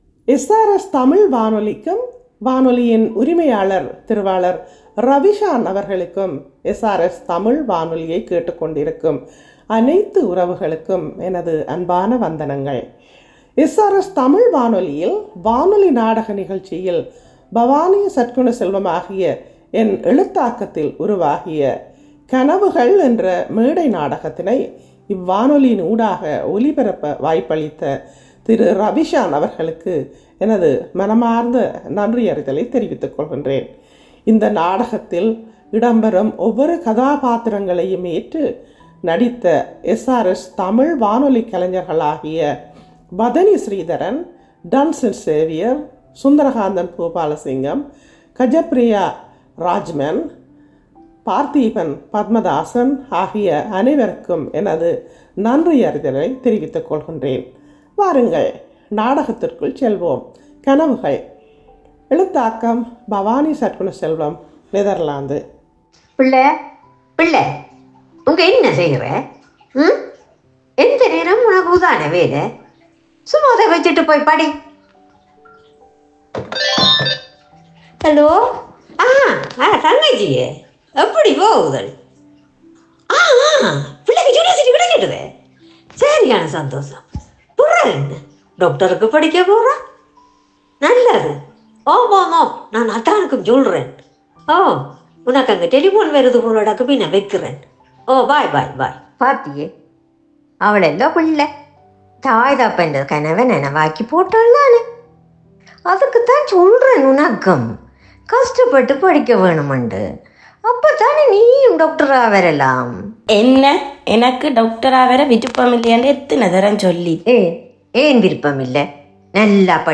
SRS தமிழ் வானொலி நாடகம் கனவுகள் எழுதியவர் பவானி சற்குணசெல்வம் 16.03.25